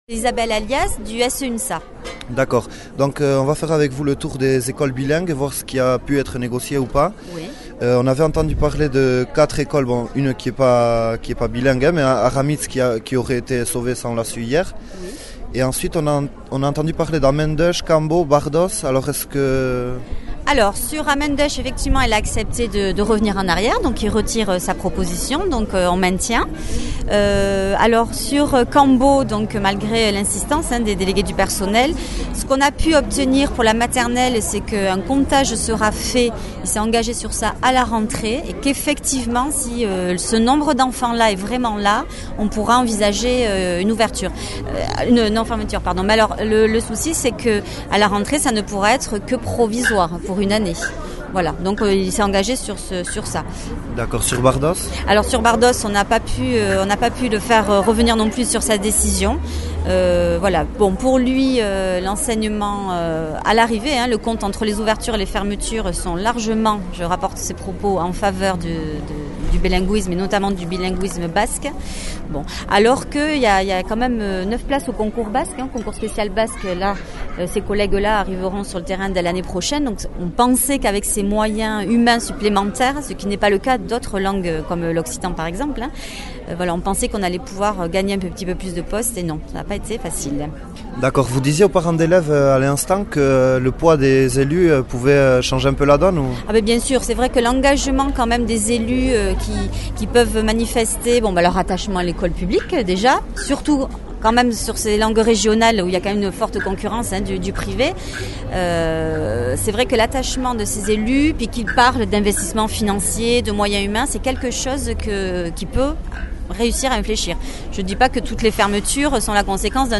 Entzün bilküratik elkitzean sindikatetako ordezkari batek erran deigüna :